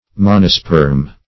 \Mon"o*sperm\